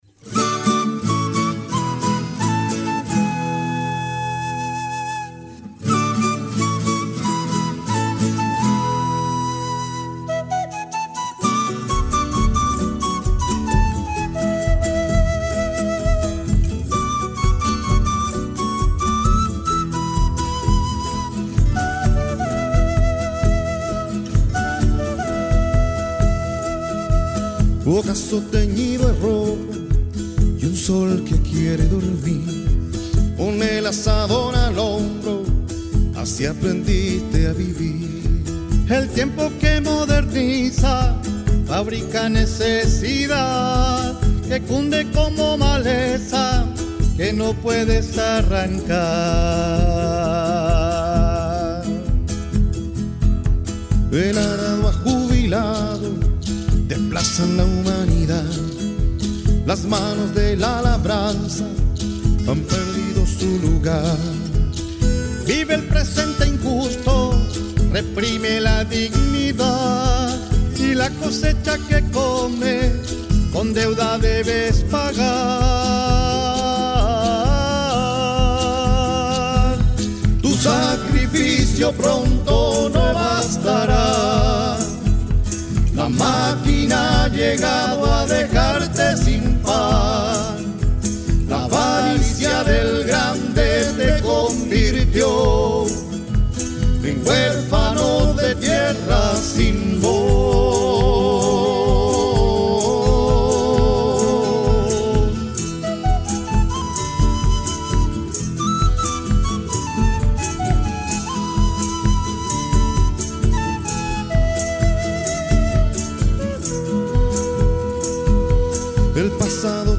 competirá “Huérfano de Tierra”, tonada